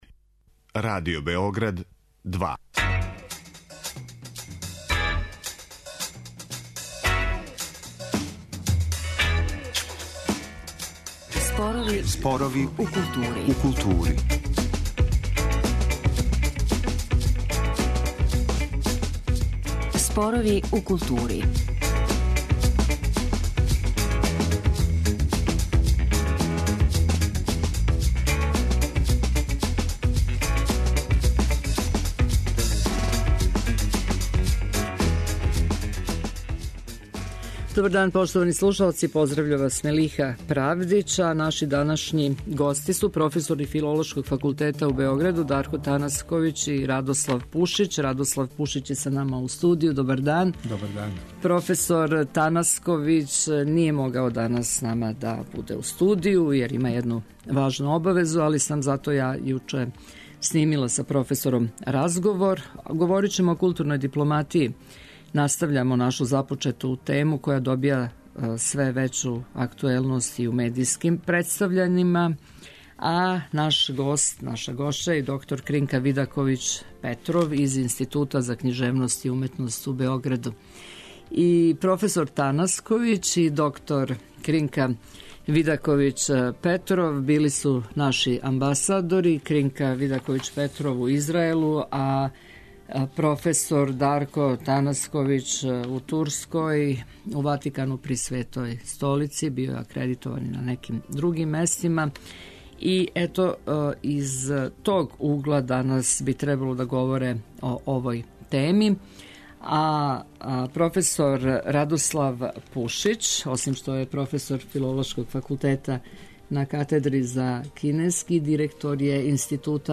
Када је реч о нашој земљи, и у овој емисији настављамо разговоре о специфичностима наше културне дипломатије. Kакве нам резултате доноси у учвршћивању позиција у међународним односима?